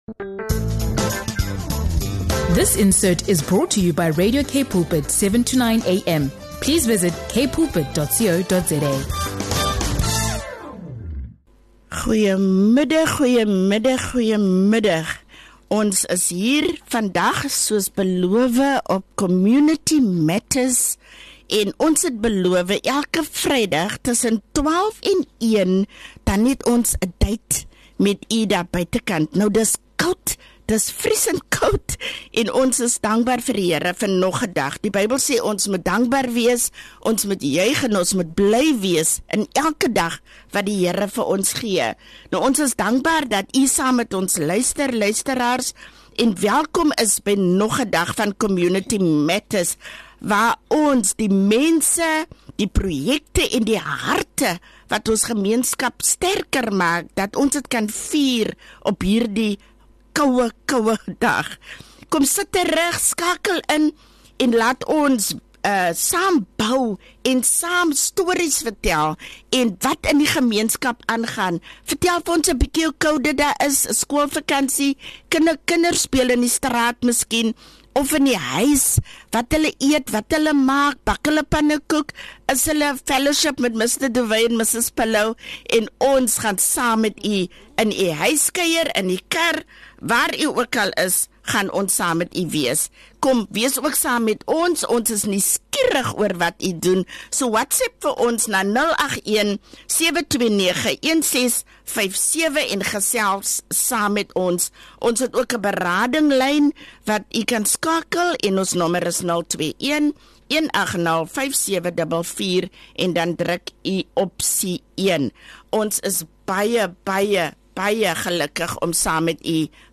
Join us for an inspiring conversation about faith, resilience, and making a difference in the community.